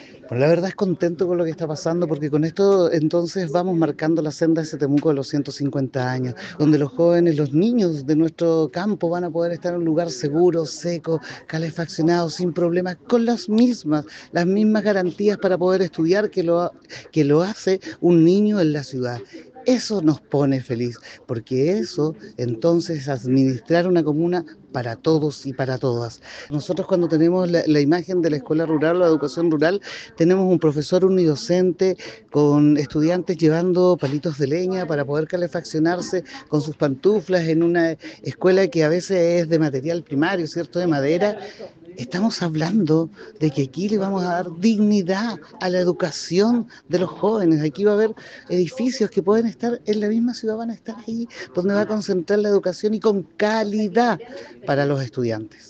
ESteban-Barriga-concejal-Temuco.mp3